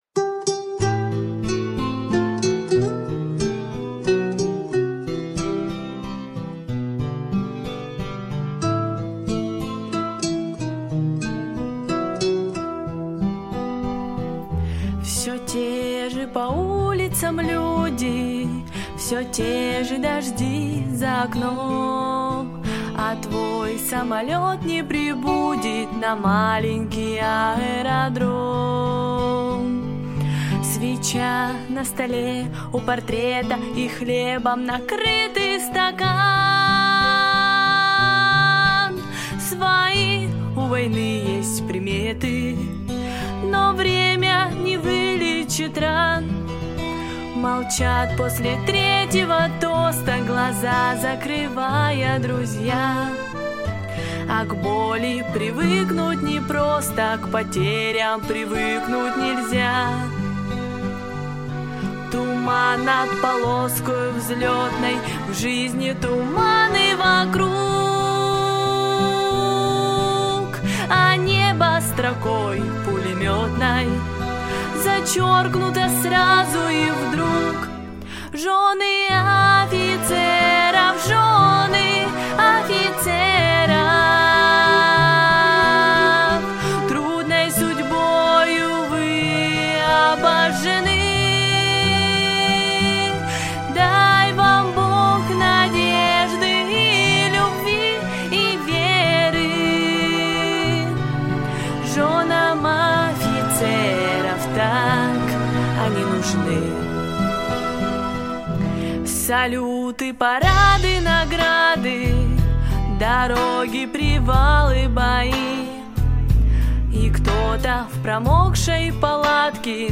• Категория: Детские песни
военные песни
Детская эстрадная вокальная студия